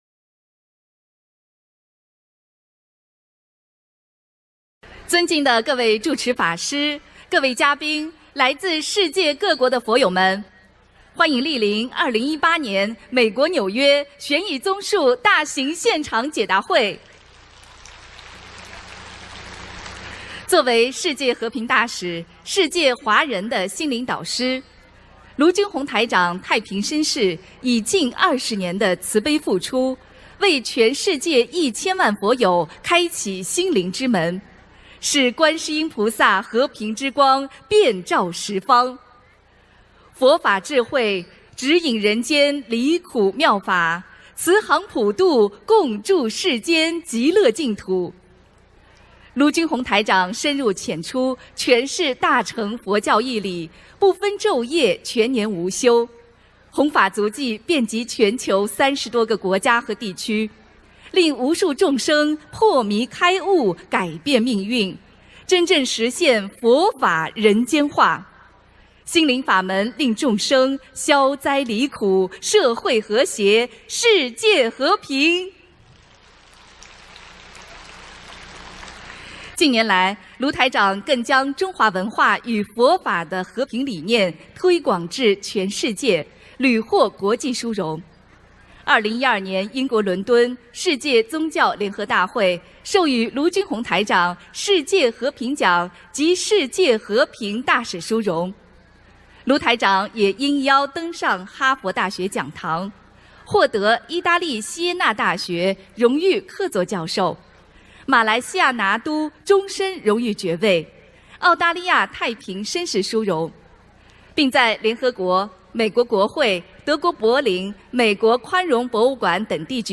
【2018年美国纽约】10月14日 大fǎ会 | 视频+音频+文字 - 2018法会合集 慈悲妙音
法会开示视频 （点击上方三角形按钮播放视频） 点击下面的标题即可收听音频 Play / pause ...